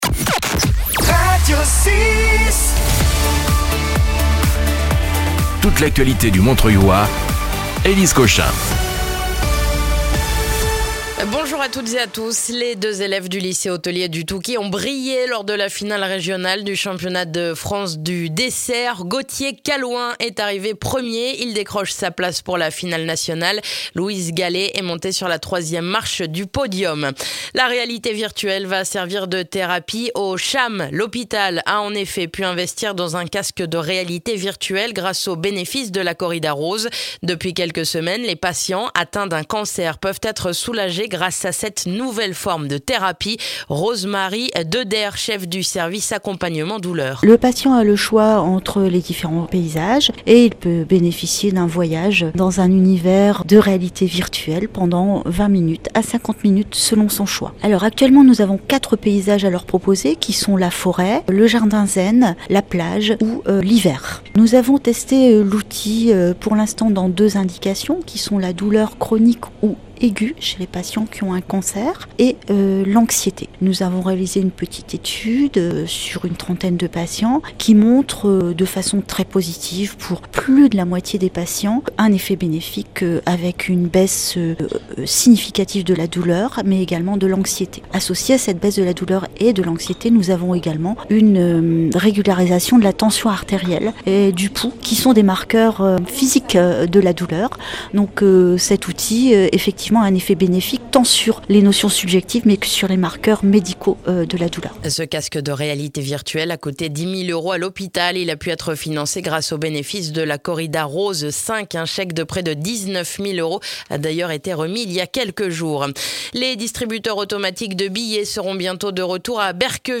Le journal du mercredi 5 février dans le montreuillois